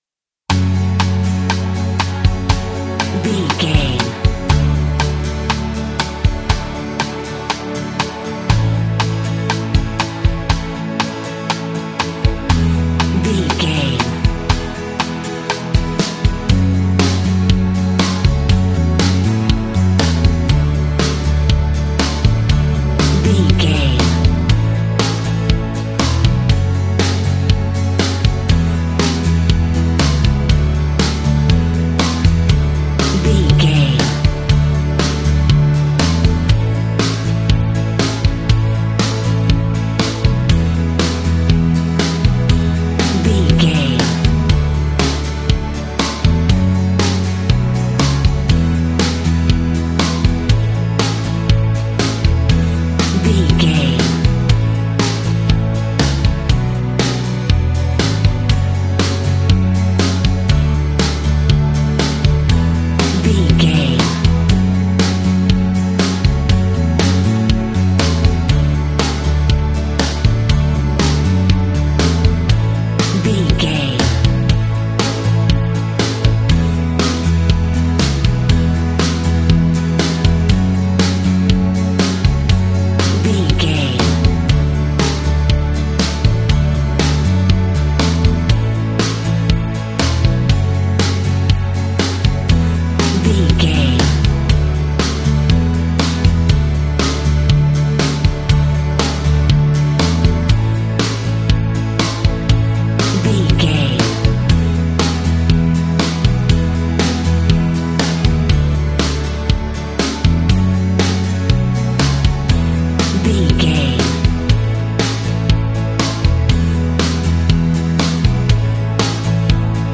Ionian/Major
fun
energetic
uplifting
instrumentals
guitars
bass
drums
organ